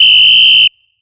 refereePig_whistle_01.ogg